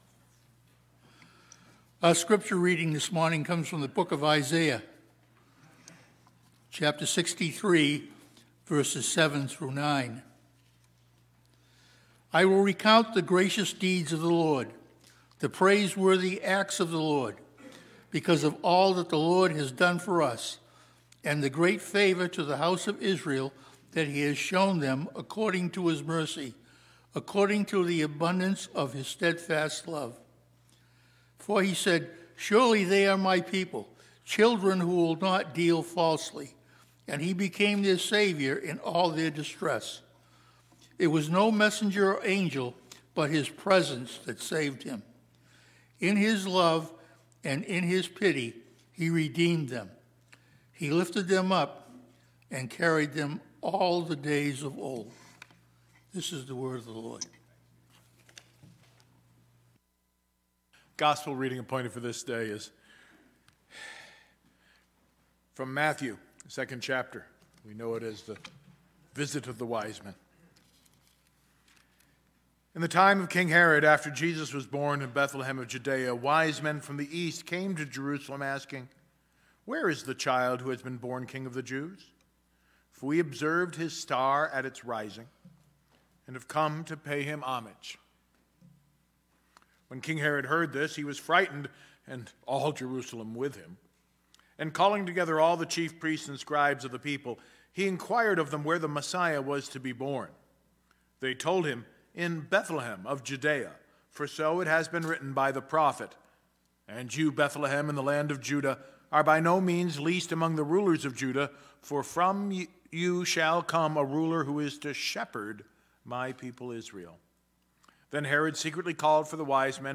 Christmas-Day-Scripture-Reading-and-Sermon.mp3